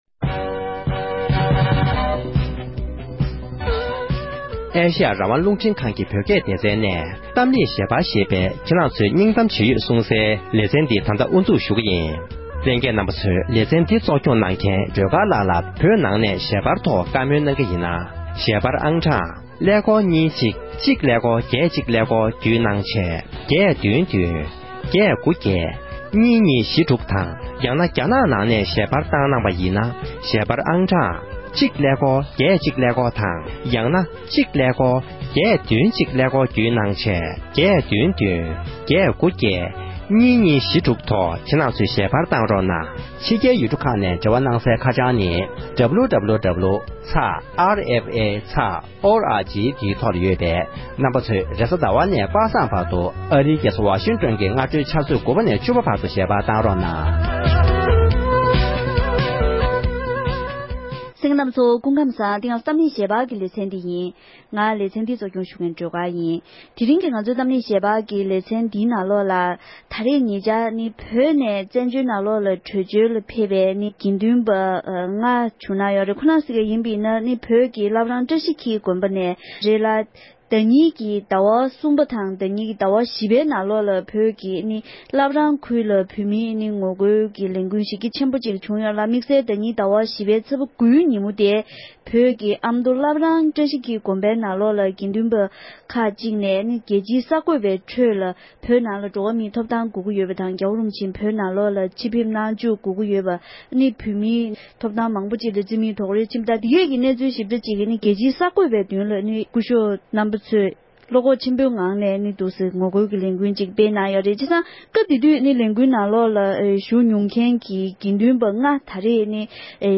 གླེང་མོལ